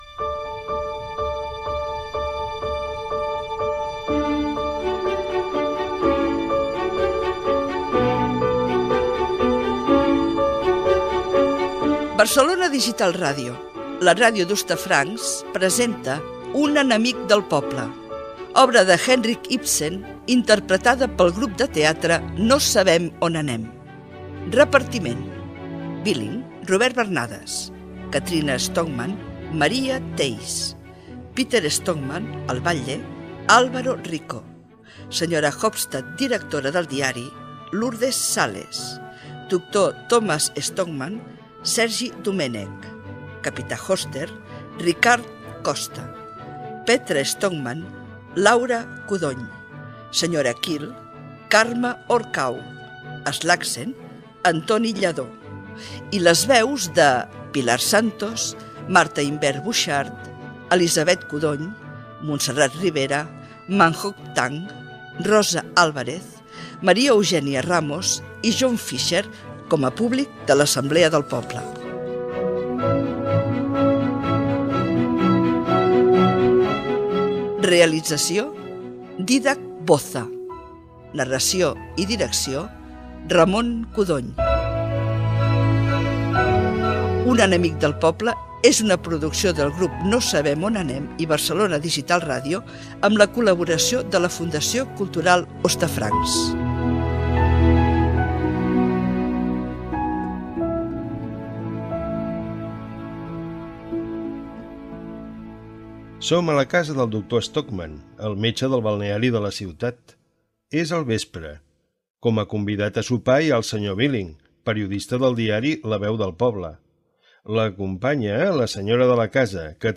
Radioteatre (Barcelona Digital Radio)
El grup de teatre ‘No sabem on anem’ adapta l’obra ‘Un enemic del poble’ (1892), d’Henrik Ibsen. Careta del programa, repartiment de l'obra, el narrador presenta els personatges i la situació i primera escena.
Ficció